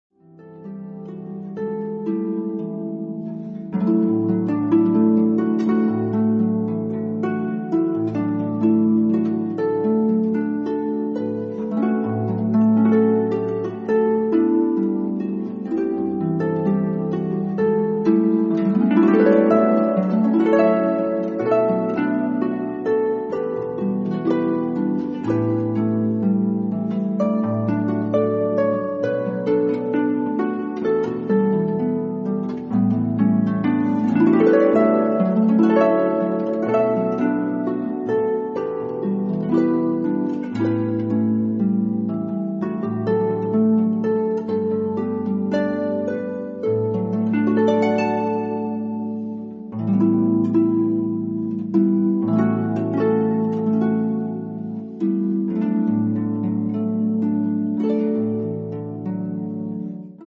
Genre: Pop & Jazz